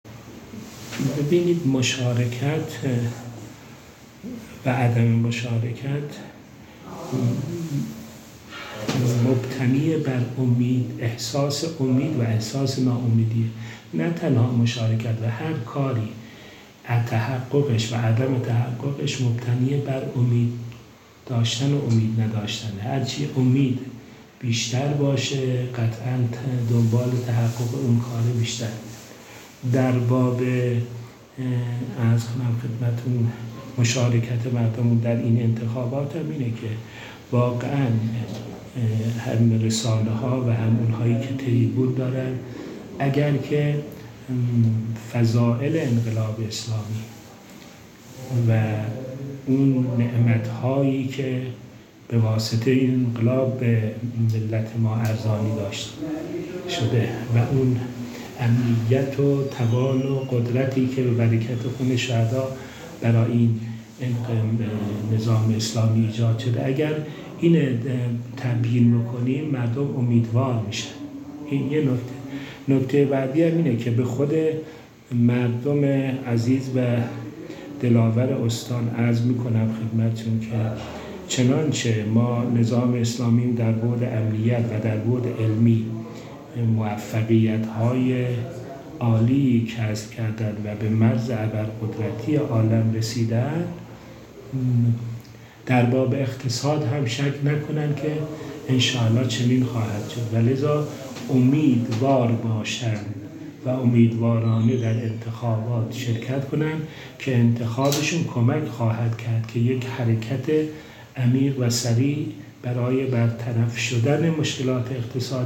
در گفت‌وگو با ایکنا از چهارمحال‌وبختیاری